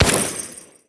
marig_fire_01.wav